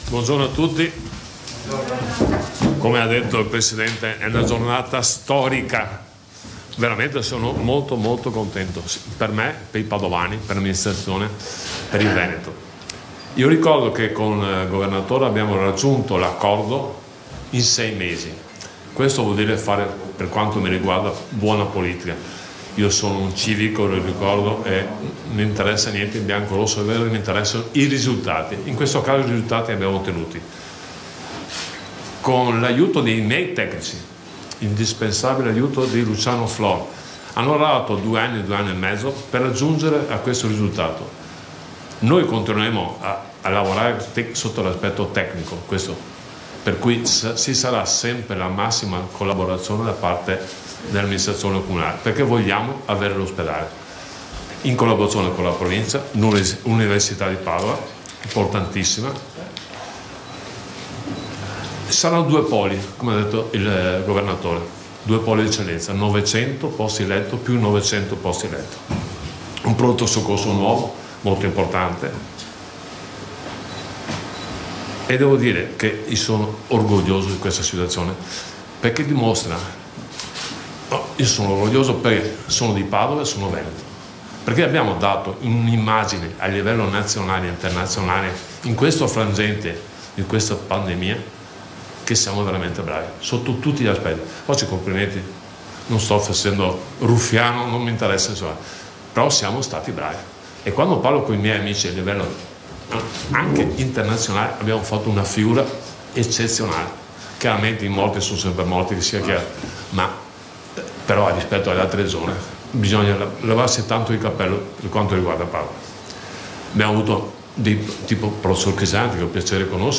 IL PUNTO DI ZAIA E L’INTERVENTO DEL SINDACO DI PADOVA DALLA CONFERENZA STAMPA DI OGGI
SINDACO-PADOVA.mp3